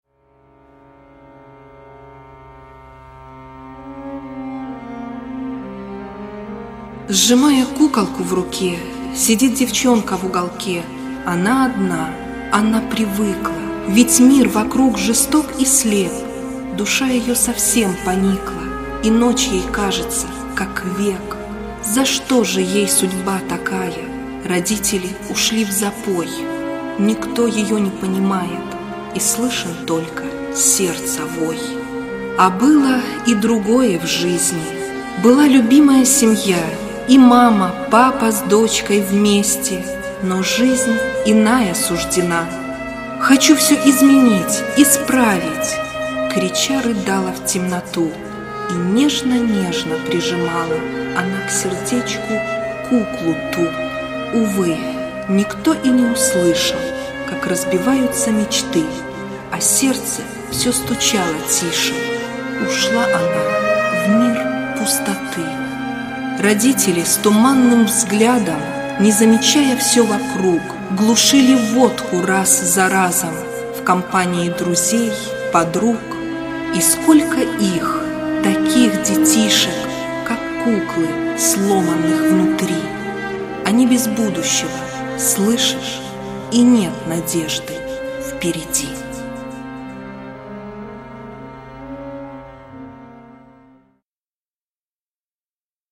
стих с музыкальным сопровождением